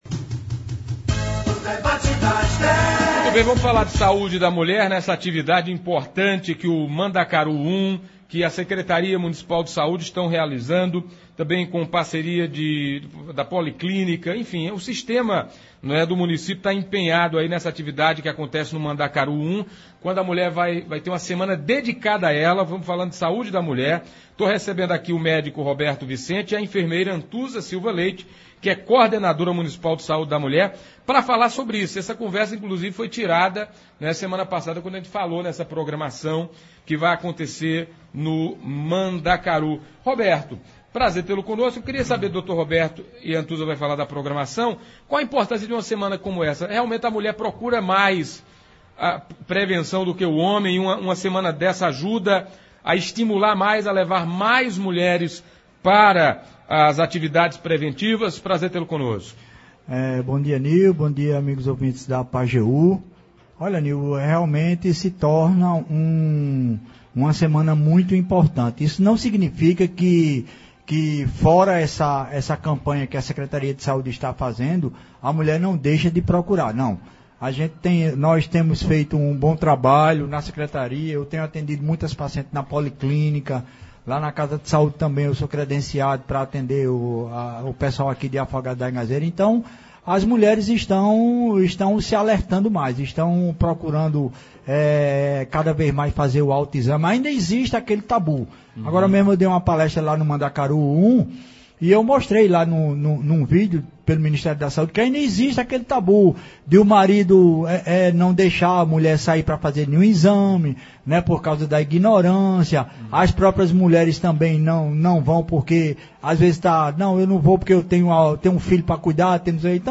Hoje (25), nos estúdios da Pajeú